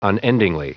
Prononciation du mot unendingly en anglais (fichier audio)
Prononciation du mot : unendingly